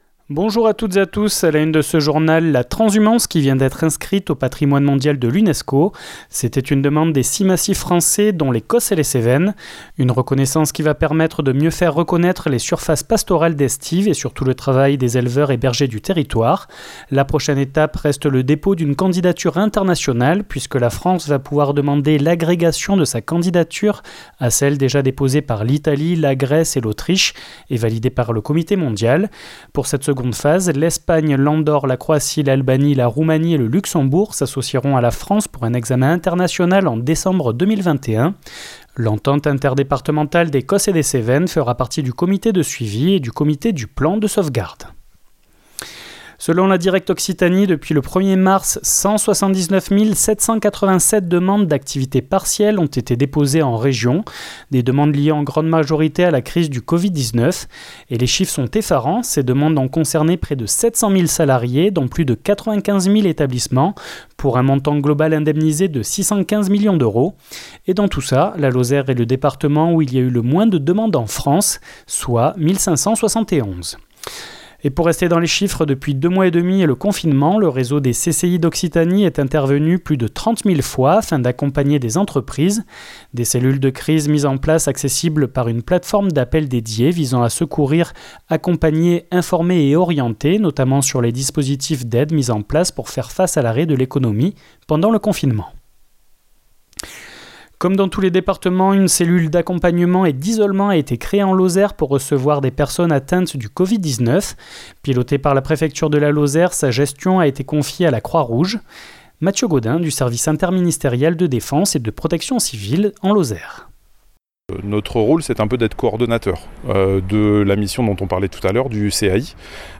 Le journal du Vendredi 5 juin 2020 | 48 FM